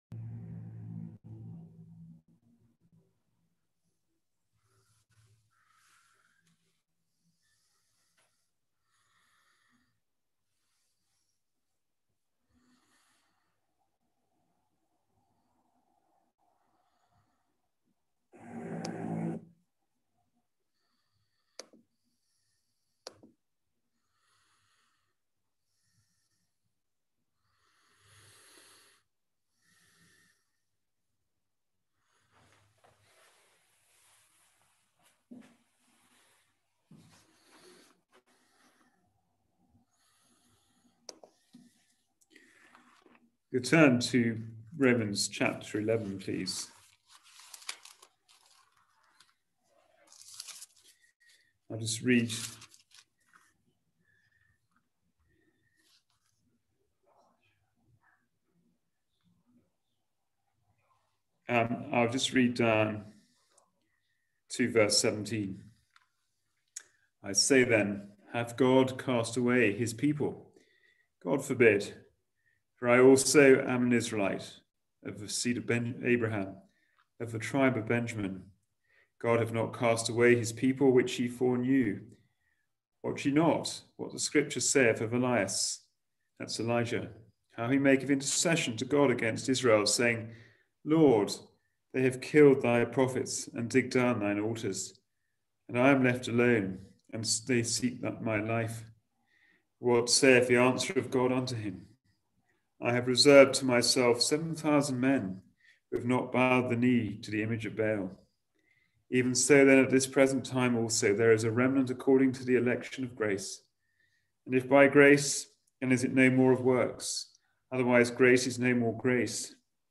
Passage: Romans 11:15 Service Type: Wednesday Bible Study